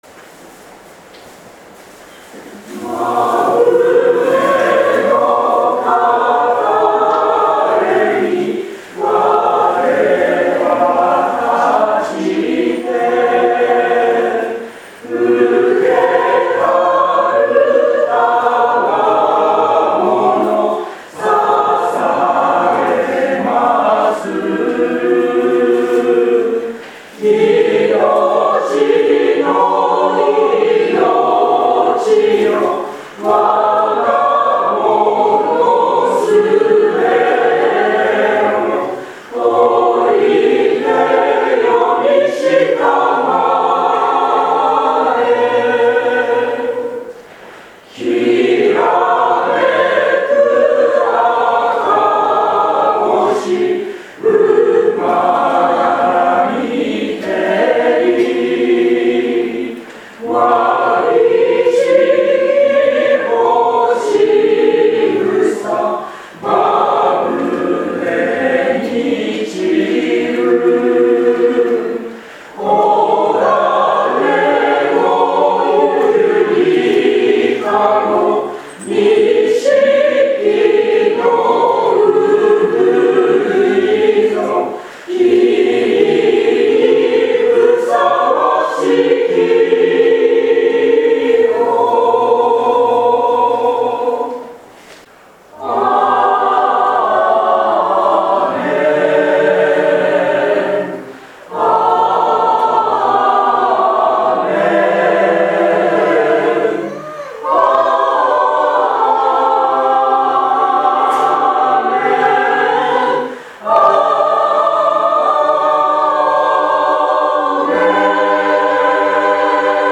聖歌隊 筑波学園教会